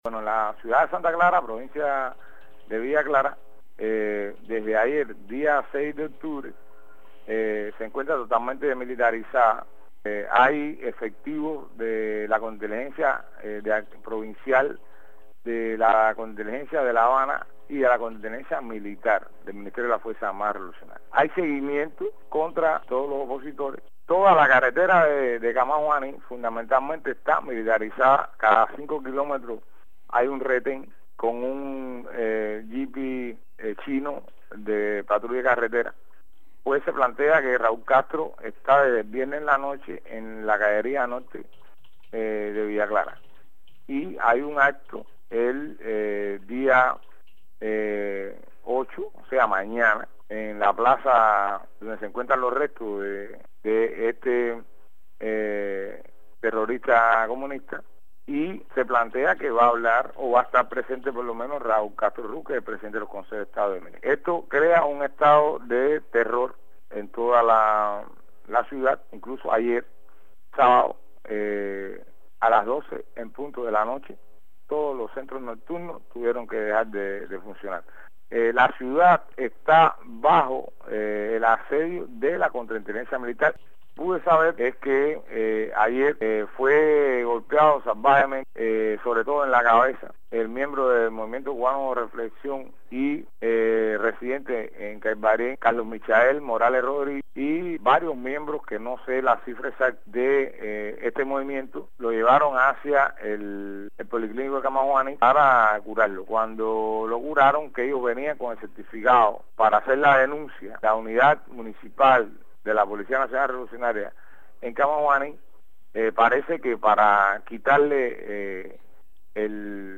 Declaraciones de Guillermo Fariñas sobre situacion en Santa Clara